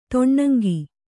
♪ toṇṇaŋgi